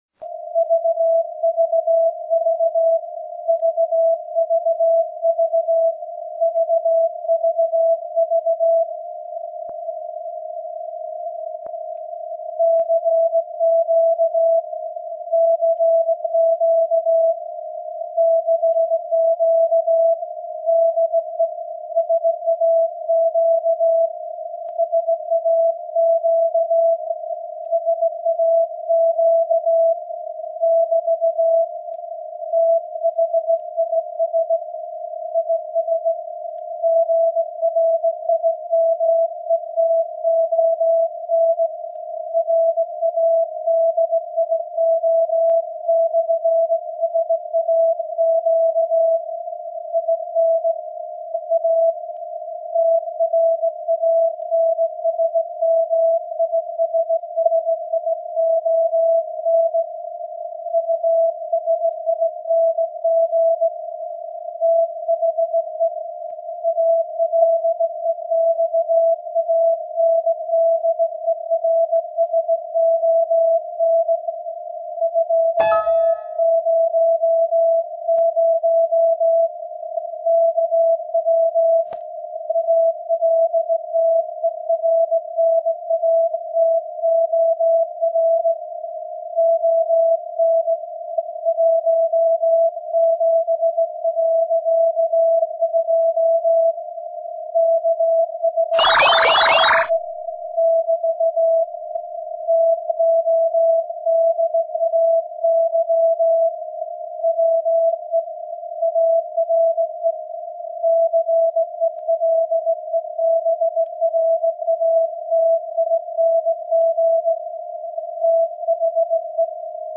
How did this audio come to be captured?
The Delta 44 card was sampling at 96 kS/s, and the 17.2 kHz of the SAQ station were well below the Nyquist limit. This is an excerpt of the audio received